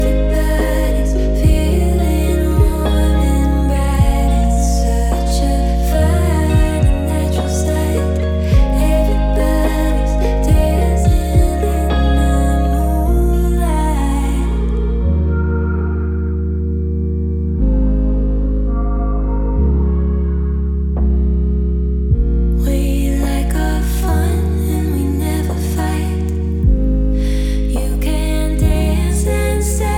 Жанр: Иностранный рок / Рок / Инди / Альтернатива
# Indie Rock